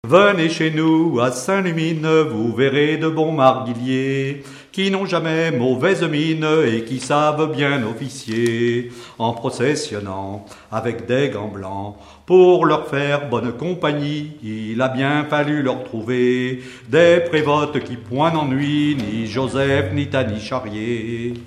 circonstance : quête calendaire
Pièce musicale inédite